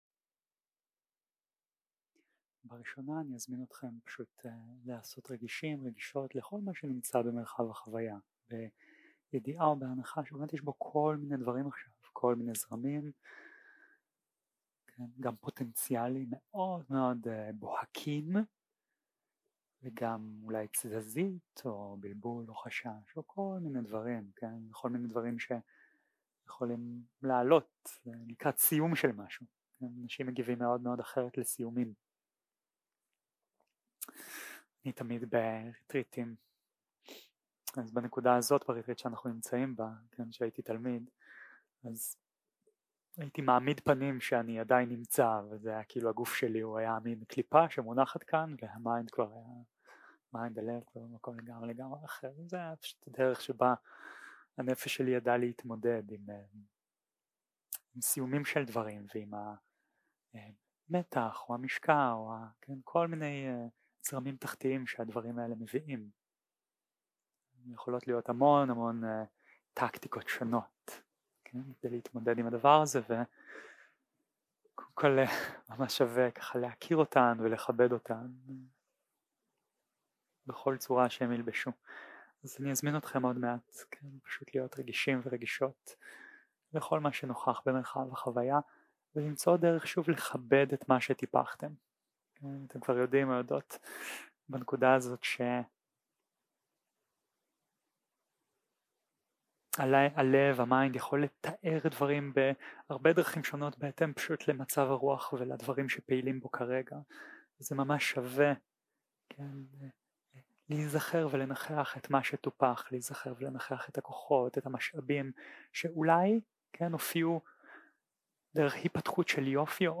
יום 10 - הקלטה 18 - בוקר - מדיטציה מונחית - שתי מדיטציות קצרות לסיום הריטריט
יום 10 - הקלטה 18 - בוקר - מדיטציה מונחית - שתי מדיטציות קצרות לסיום הריטריט 0:00 0:00 סוג ההקלטה: Dharma type: Guided meditation שפת ההקלטה: Dharma talk language: Hebrew